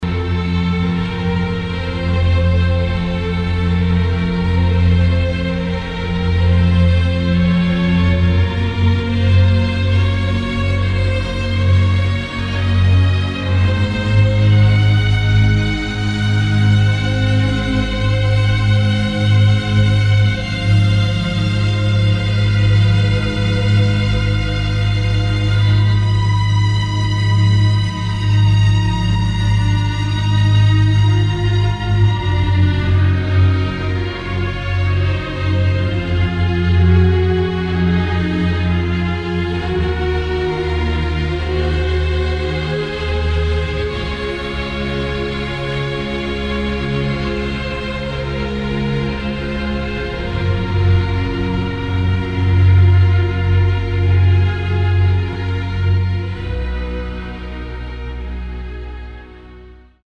Je recherche désésperement le titre de ce morceau classique :